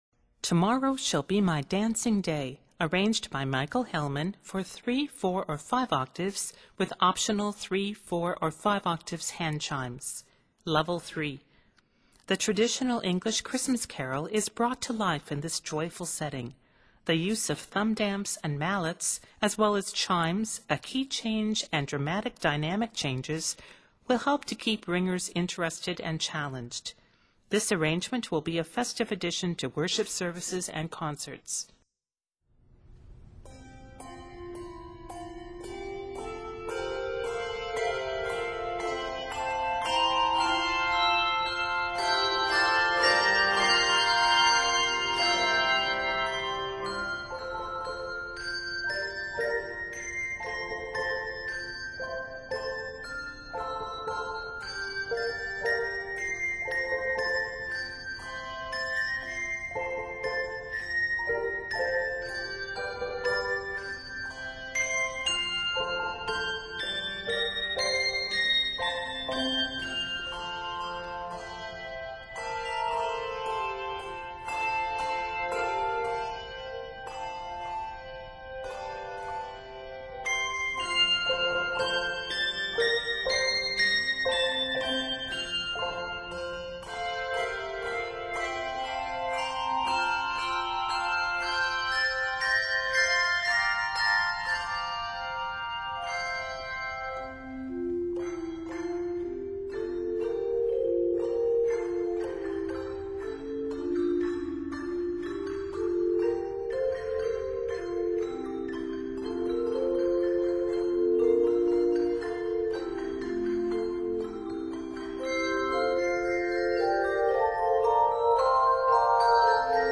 Scored in F Major and C Major, this piece is 106 measures.